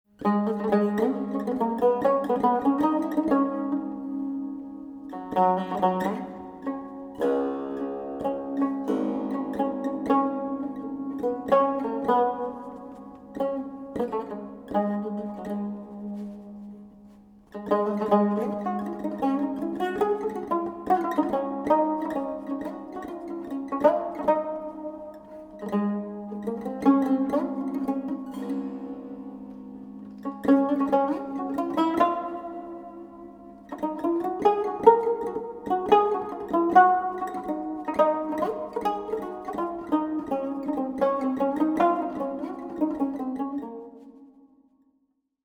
Recorded on November 25 & 26, 2000 in Santa Cruz, California
Genre: Early Music, Ottoman Classical.
Improvisation (tanbur solo